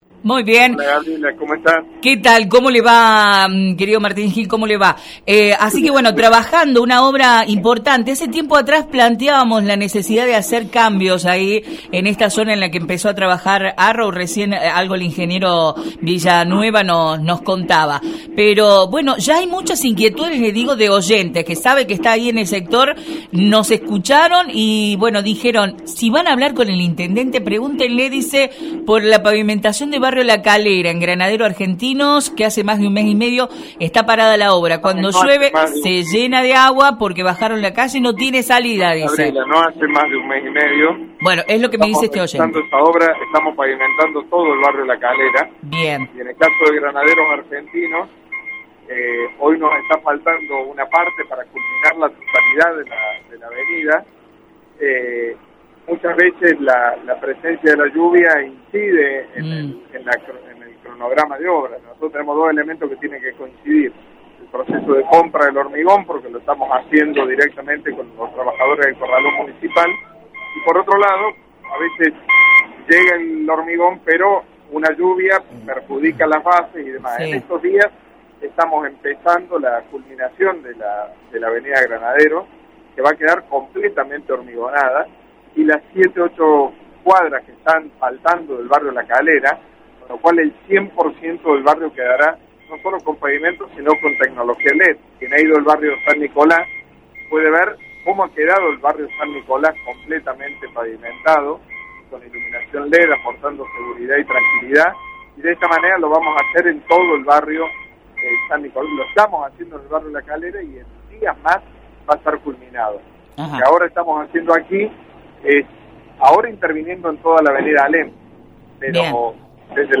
Aprovechando el recorrido que el intendente Martín Gill hizo por la obra de repavimentación en la zona desde Bv. Vélez Sarsfield por Alem y hasta Rawson, dialogamos en exclusiva con el Intendente Martín Gill para conocer detalles de esa obra y algunas inquietudes planteadas por nuestros oyentes.